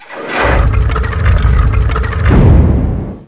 distant_gears.wav